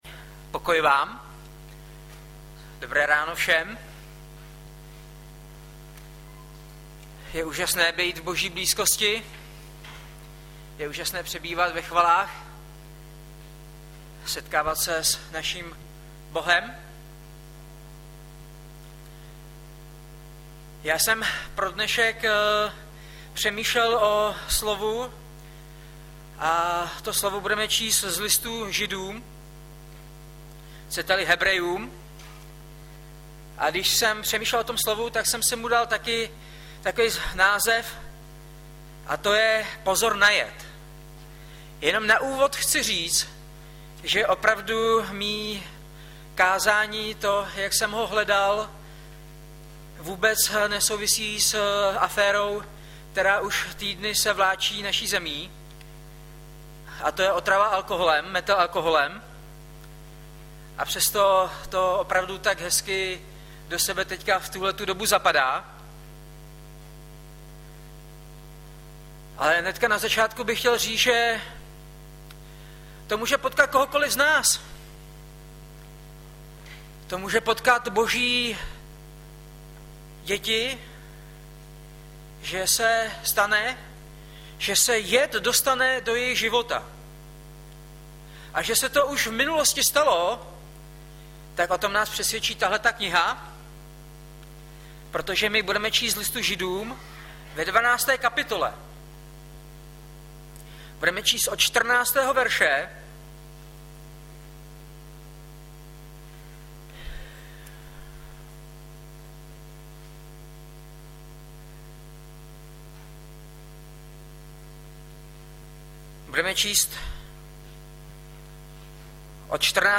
Hlavní nabídka Kázání Chvály Kalendář Knihovna Kontakt Pro přihlášené O nás Partneři Zpravodaj Přihlásit se Zavřít Jméno Heslo Pamatuj si mě  30.09.2012 - POZOR NA JED - Žd 12,14-17 Audiozáznam kázání si můžete také uložit do PC na tomto odkazu.